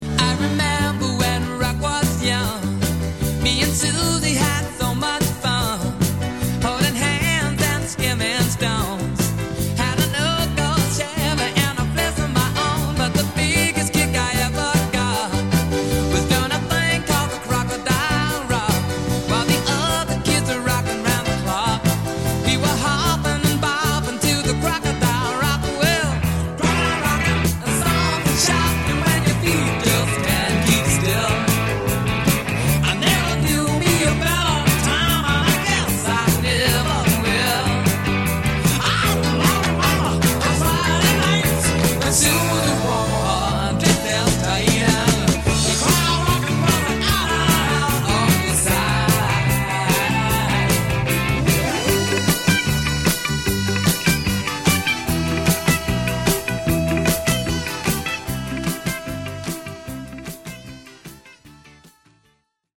è un pezzetto divertente e dissacratore